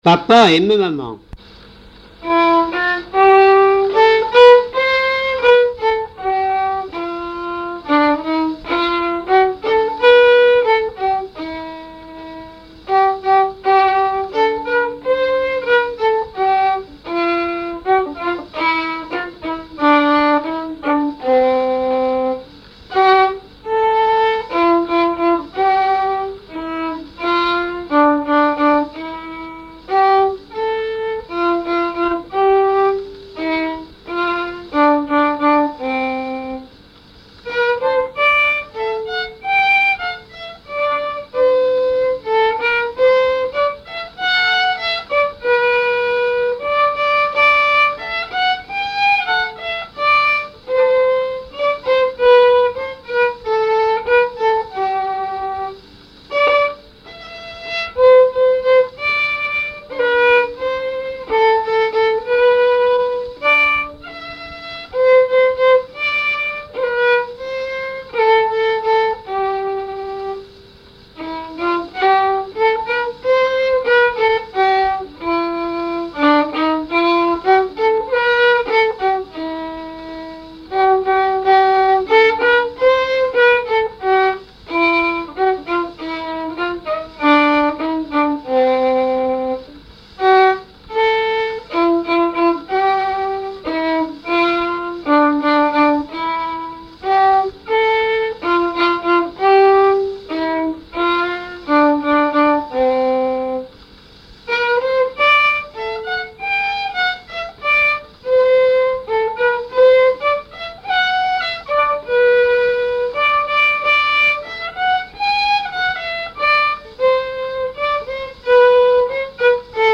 Genre strophique
recherche de répertoire de violon pour le groupe folklorique
Pièce musicale inédite